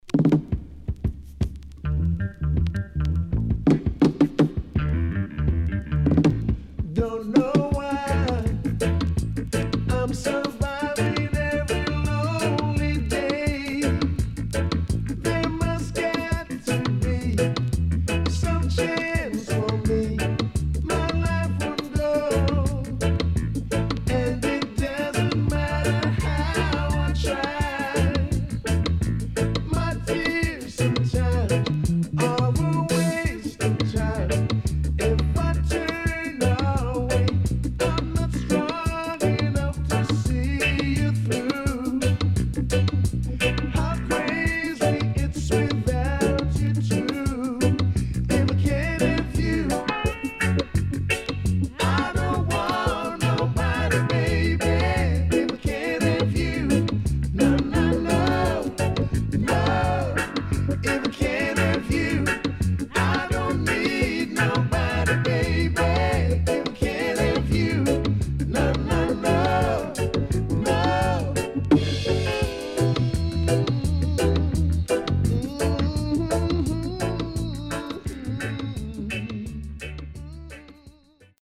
【12inch】
SIDE A:少しチリノイズ入ります。